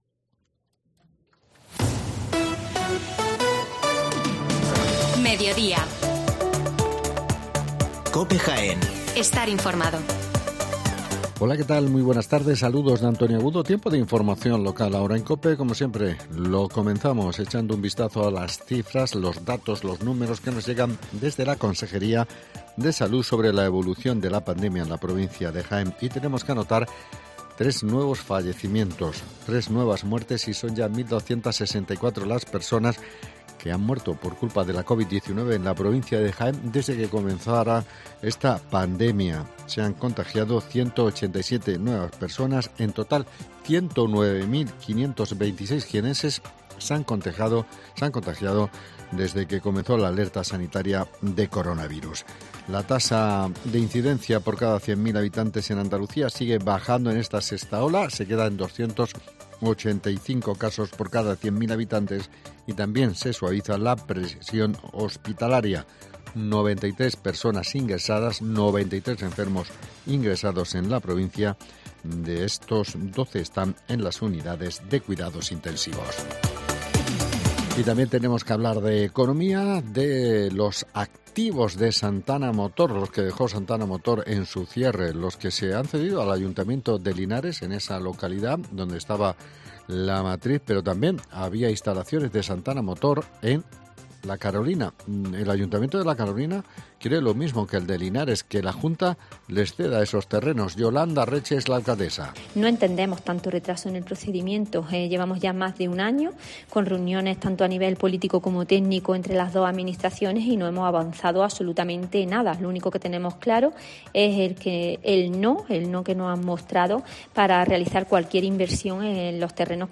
Las noticias locales